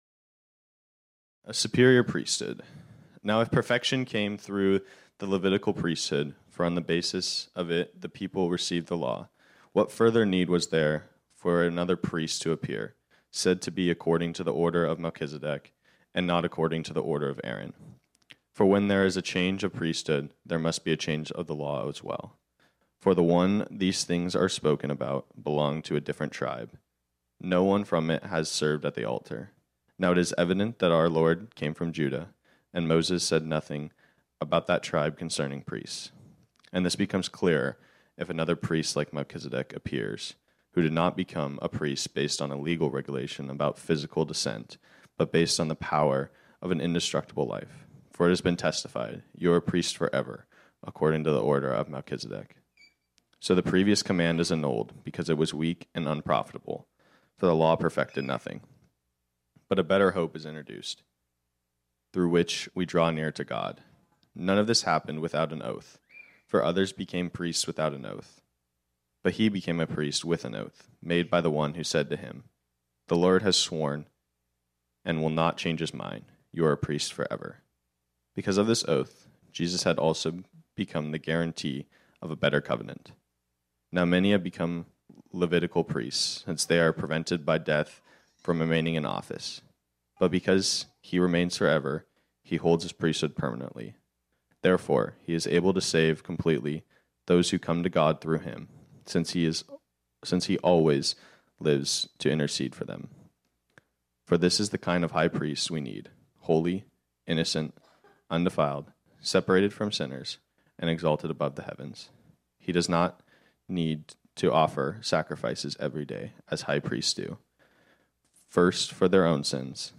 This sermon was originally preached on Sunday, January 1, 2023.